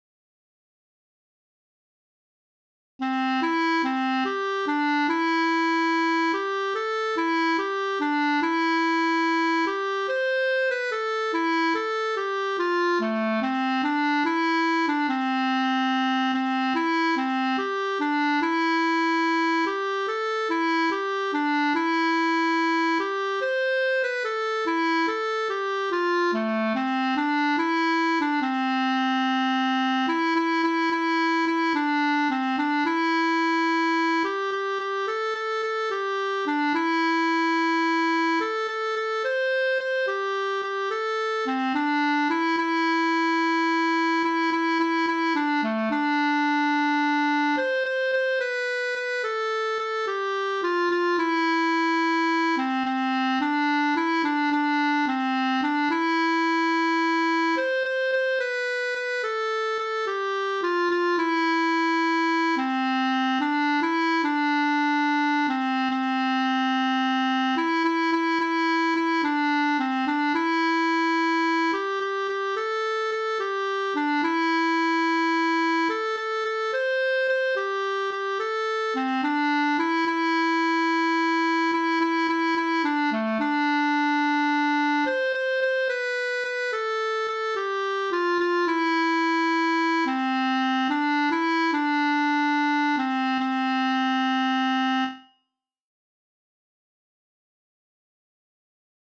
Finale Clarinet playout